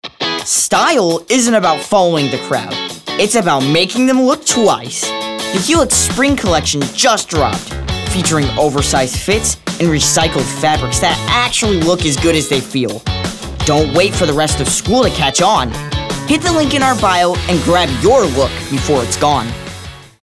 announcer, confident, cool, high-energy, perky, retail, teenager, upbeat